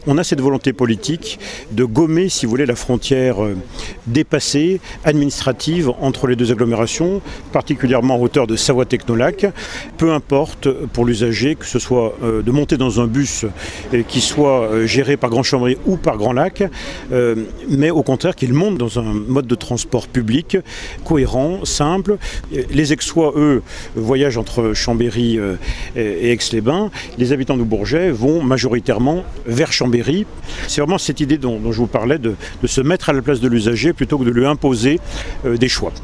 Un pas de plus vers un rapprochement des deux agglos chambériennes et aixoises dont se félicite Renaud Berreti, maire d’Aix-les-Bains et Président de Grand Lac :